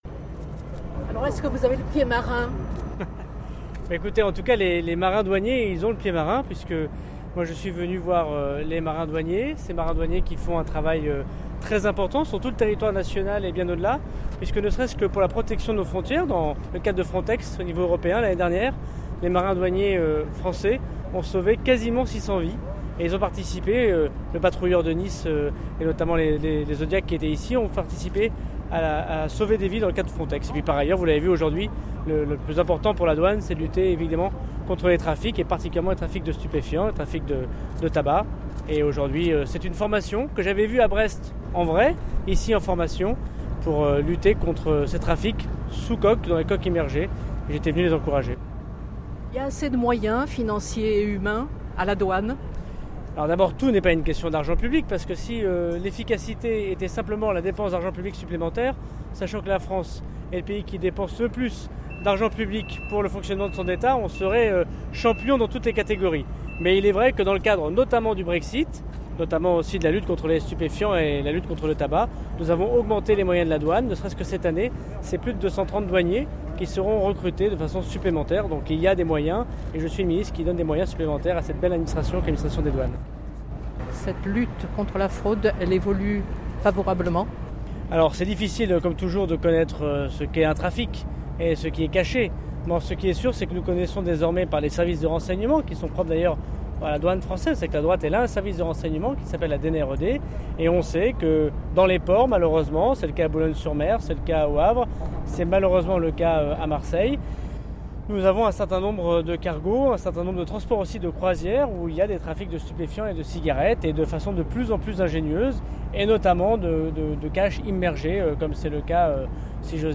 Entretien avec le ministre Gérald Darmanin qui a pris place à bord d’un bateau de la douane.